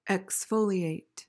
PRONUNCIATION:
(eks-FO-lee-ayt)